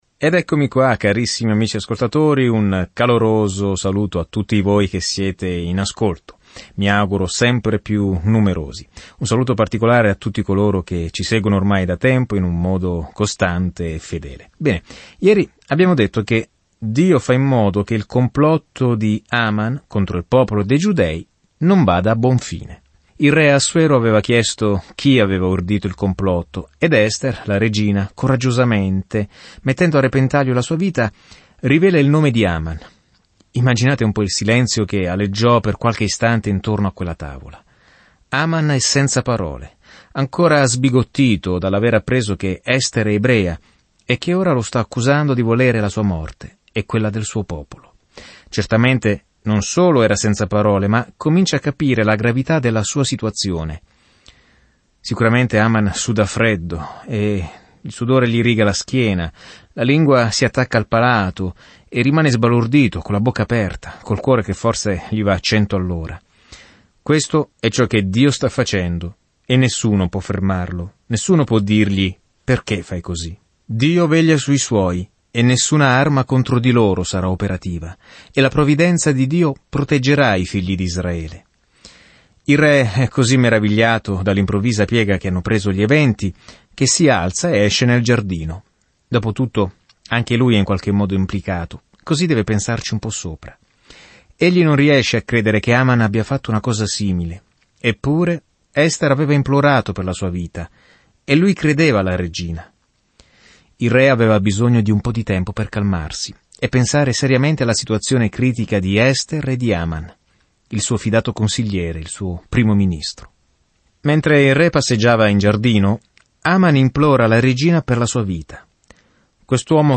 Scrittura Ester 7:9-10 Ester 8:1-12 Giorno 8 Inizia questo Piano Giorno 10 Riguardo questo Piano Dio si è sempre preso cura del suo popolo, anche quando complotti genocidi ne minacciano l’estinzione; una storia incredibile di come una ragazza ebrea affronta la persona più potente del mondo per chiedere aiuto. Viaggia ogni giorno attraverso Ester mentre ascolti lo studio audio e leggi versetti selezionati della parola di Dio.